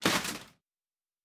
Metal Foley Impact 4.wav